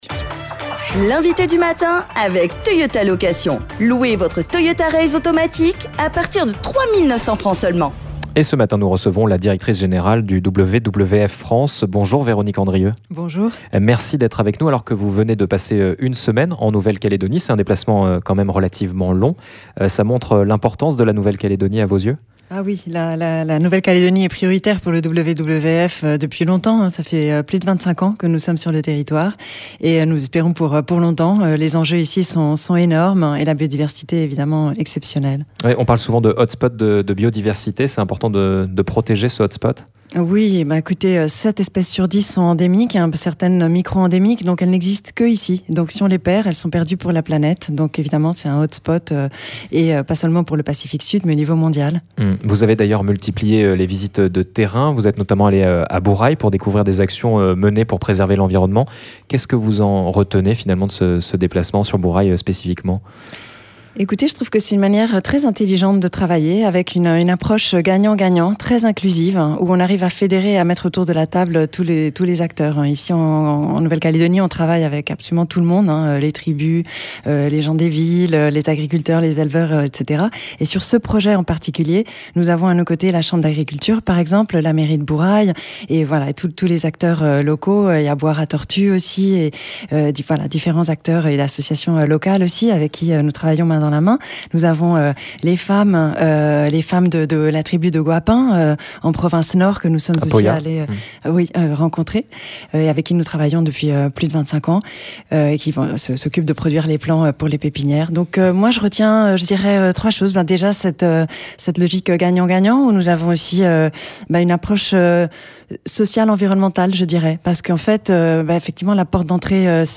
Elle était notre invitée pour dresser un premier bilan de sa visite, et revenir sur les temps forts de son déplacement.